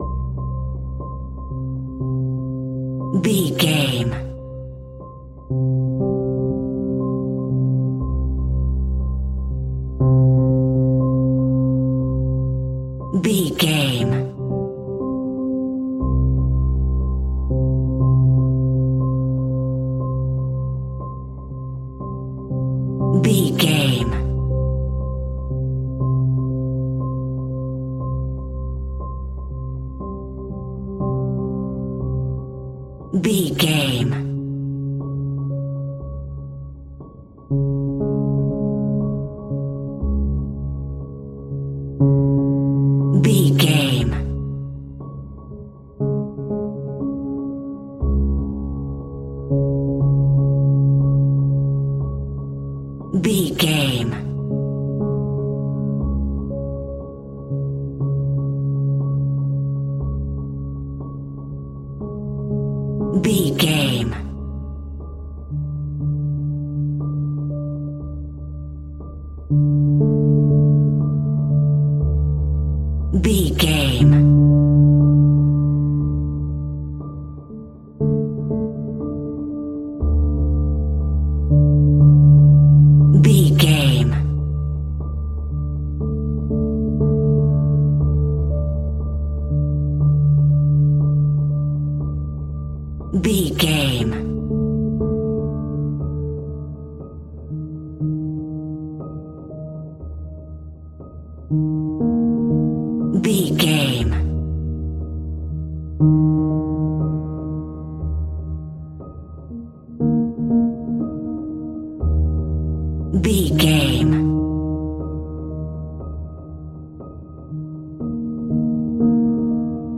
Tension on the Piano.
In-crescendo
Thriller
Aeolian/Minor
ominous
haunting
eerie
electric piano
mysterious